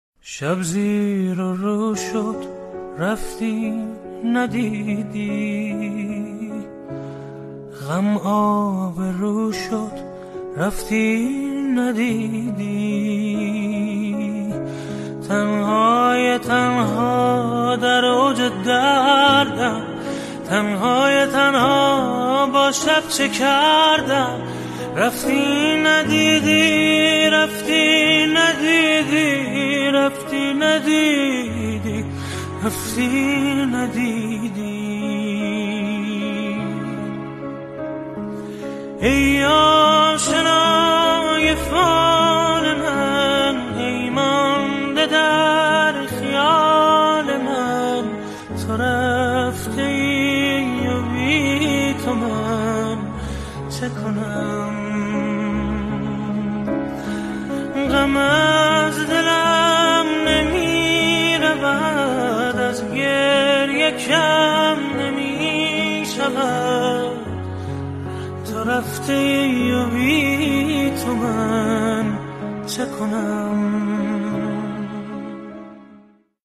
ترانه‌ای که حس‌های عمیق دوری و دل‌تنگی را به گوش می‌رساند.